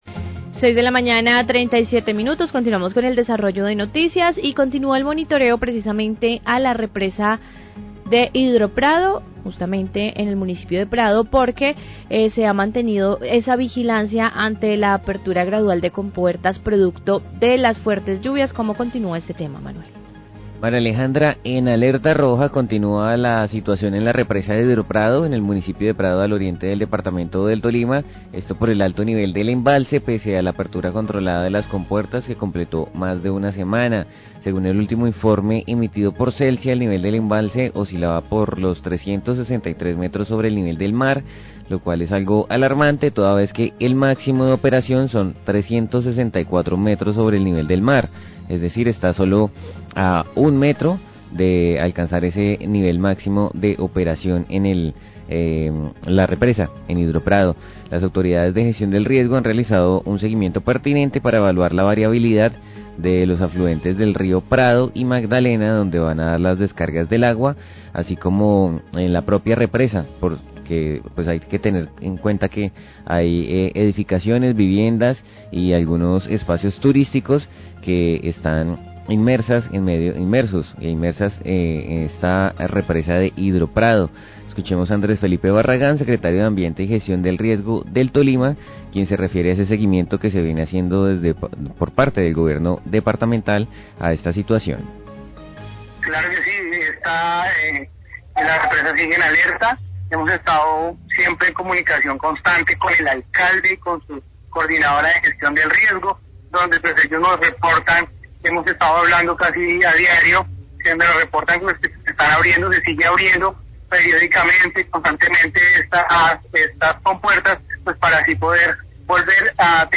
Radio
Celsia continua el monitoreo de la represa de Prado debido al alto nivel del embalse generado por las fuertes lluvias  a pesar de la apertura controlada de compuertas que realizó la empresa. Andrés Felipe Barragán, Secretario de Gestión del Riesgo del Tolima, habla del monitoreo de la situación del embalse.